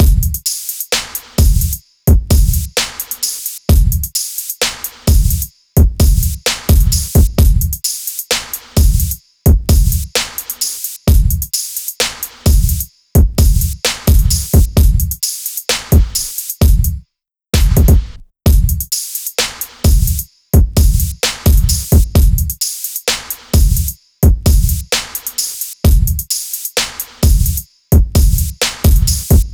beat.wav